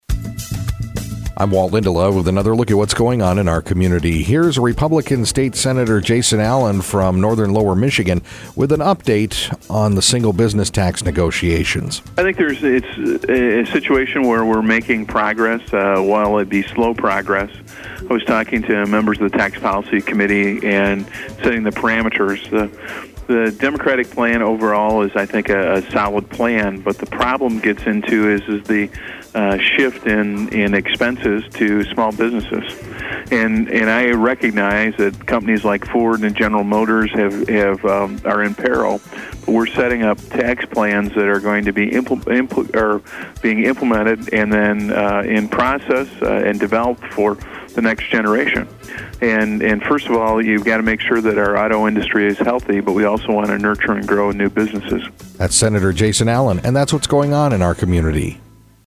INTERVIEW: State Senator Jason Allen